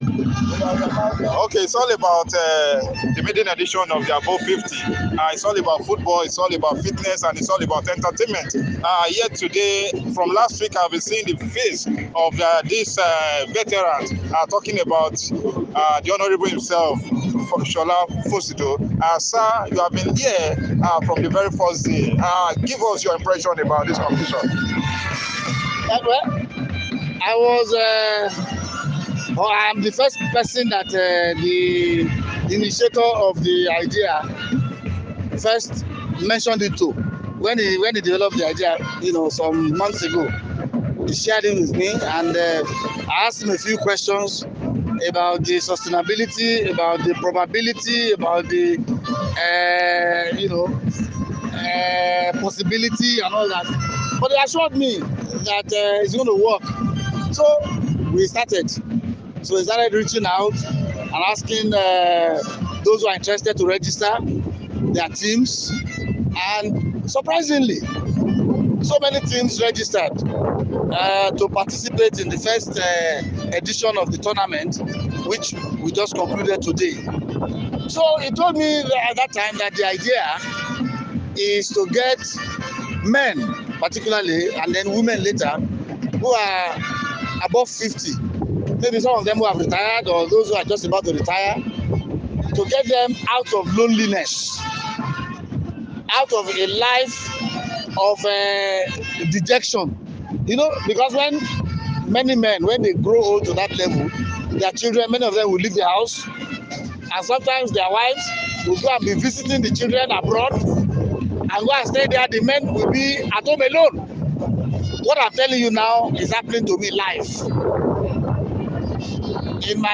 In an exclusive interview with Sports247, the distinguished Nigerian dramatist, scholar, critic, film actor, and director, Professor Sola Fosudo, shared his insights on the recently concluded maiden edition of the Above 50 football tournament.